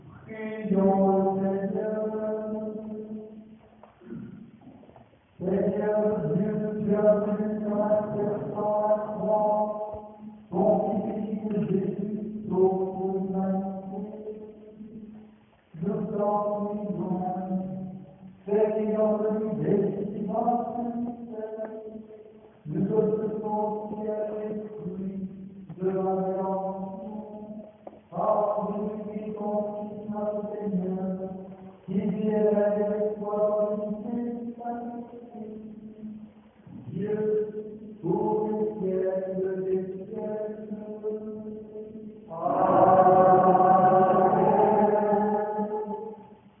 Sons enregistrés lors de la cérémonie :